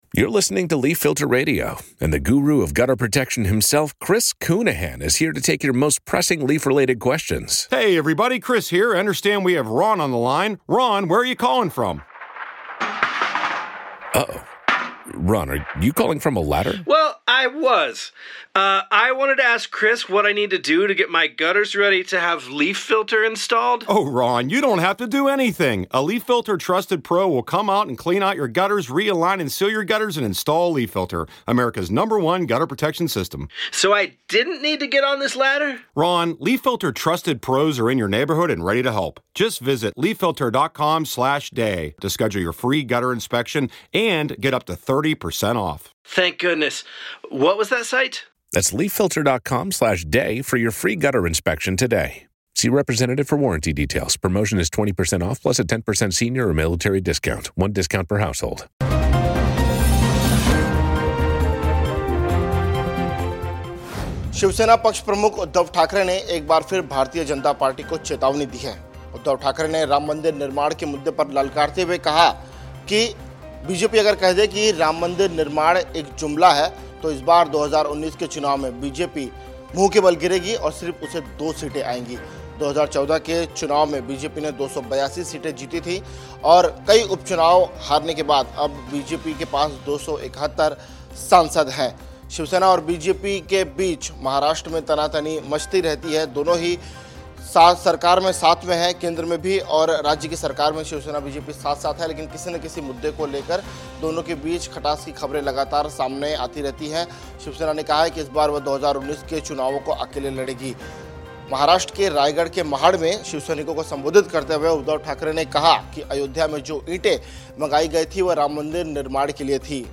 न्यूज़ रिपोर्ट - News Report Hindi / शिवसेना उद्धव ठाकरे- 2019 चुनाव में मुंह के बल गिरेगी बीजेपी!